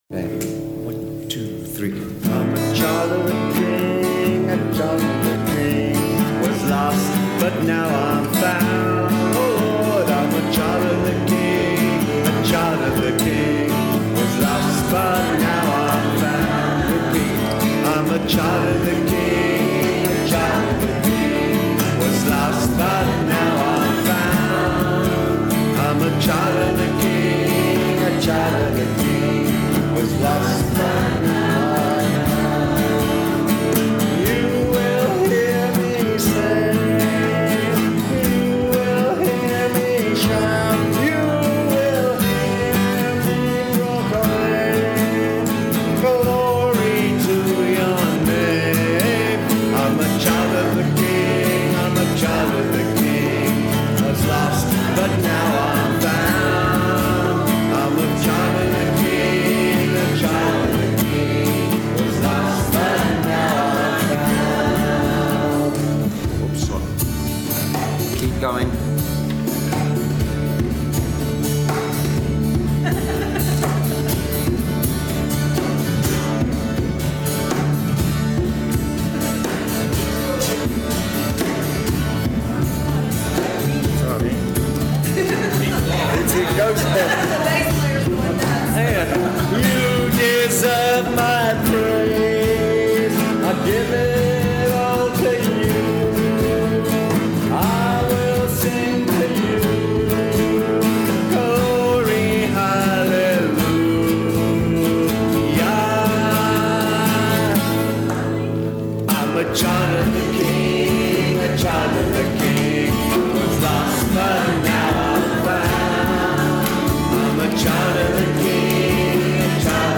A Family Worship Song